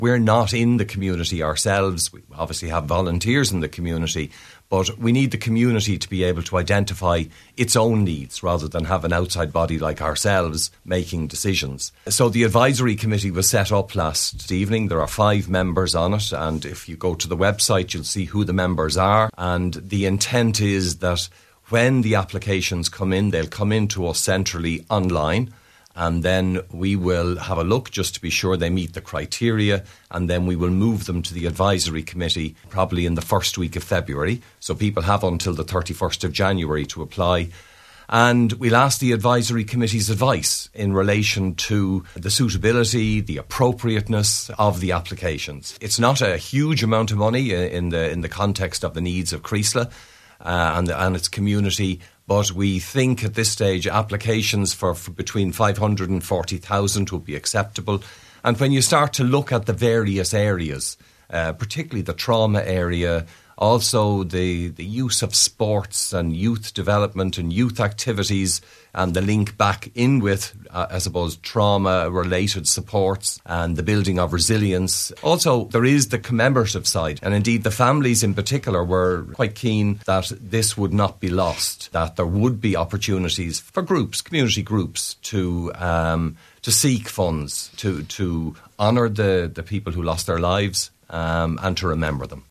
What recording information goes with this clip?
spoke on today’s Nine til Noon Show after two meetings in Creeslough last night.